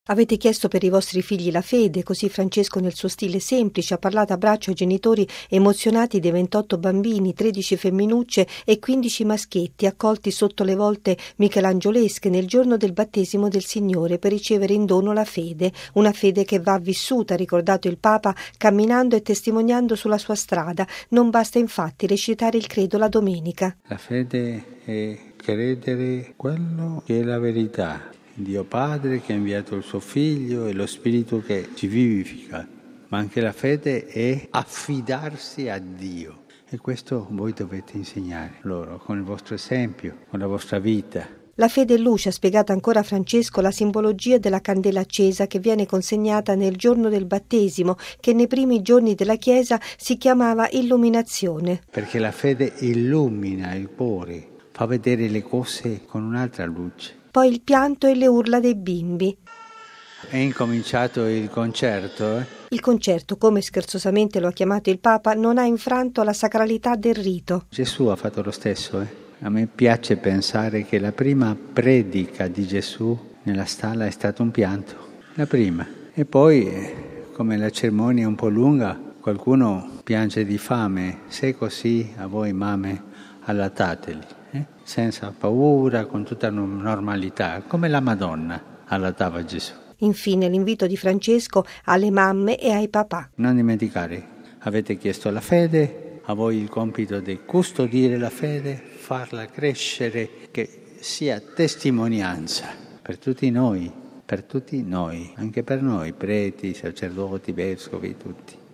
Nella solenne cornice della Cappella Sistina, le grida dei bimbi, che stamani hanno ricevuto il Battesimo, durante la Messa presieduta dal Papa nel giorno che chiude il Tempo di Natale, nella prima domenica dopo l’Epifania, in ricordo del Battesimo di Gesù sulle rive del Giordano ad opera di San Giovanni Battista.
Poi il pianto dei bambini e le loro urla, il “concerto”, come - scherzosamente - lo ha chiamato il Papa, non hanno infranto la sacralità del rito: